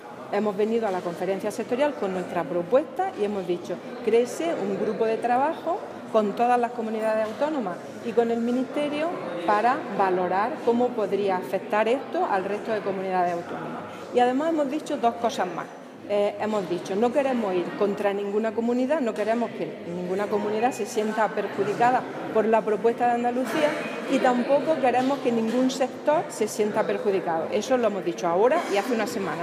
Declaraciones Carmen Ortiz grupo de trabajo